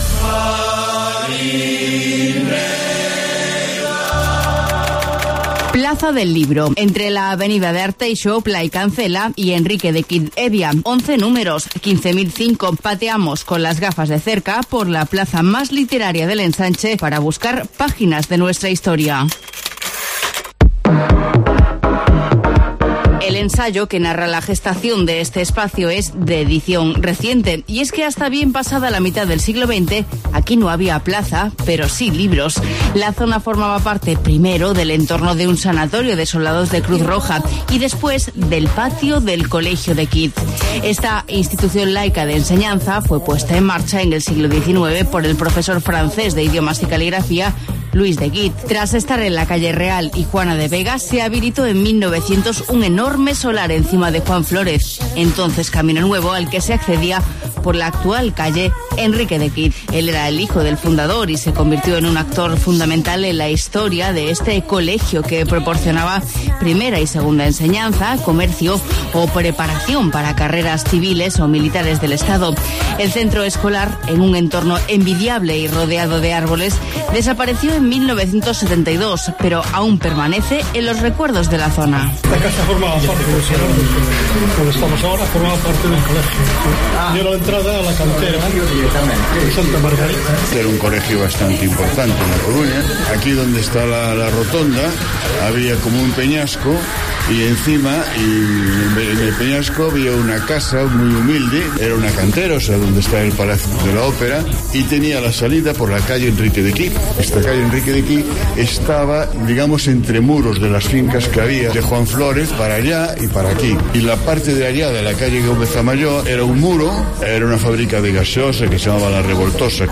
Hoy en Pateando A Coruña nos hemos ido a la Plaza del Libro .